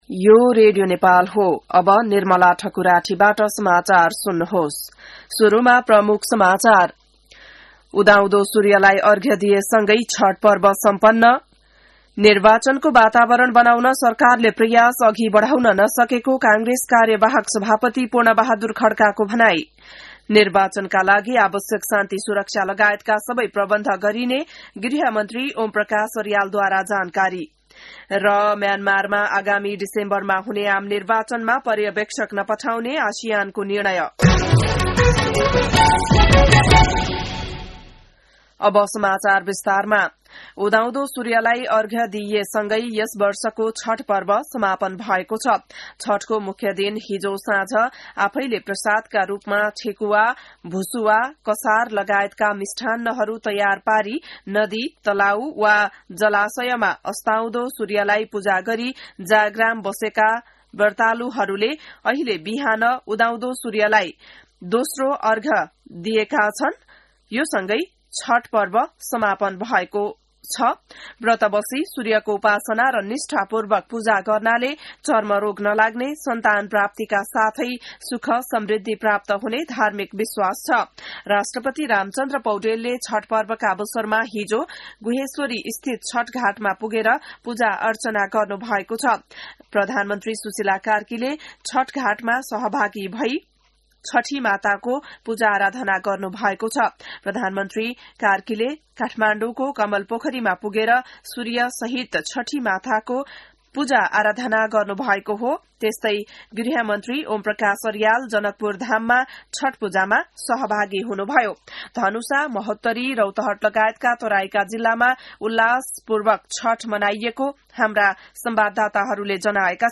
बिहान ९ बजेको नेपाली समाचार : ११ कार्तिक , २०८२